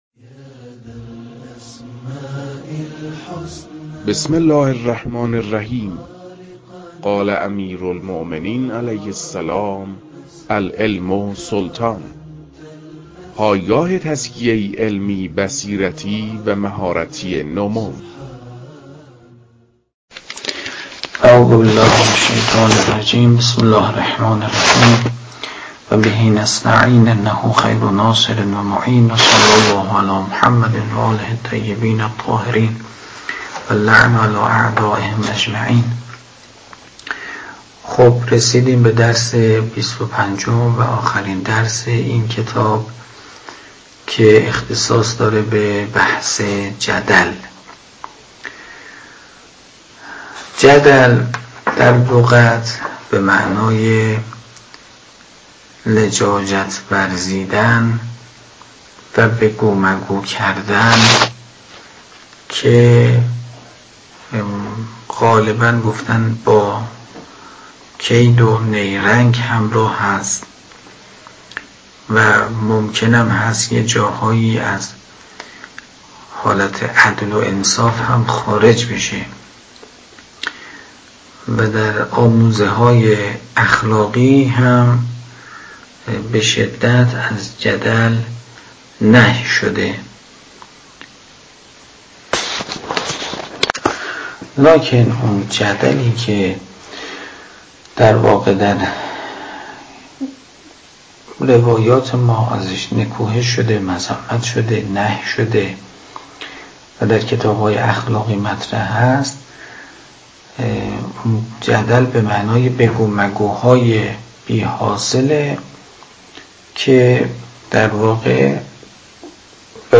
در این بخش، کتاب «درآمدی بر منطق» که اولین کتاب در مرحلۀ آشنایی با علم منطق است، به صورت ترتیب مباحث کتاب، تدریس می‌شود.
در تدریس این کتاب- با توجه به سطح آشنایی کتاب- سعی شده است، مطالب به صورت روان و در حد آشنایی ارائه شود.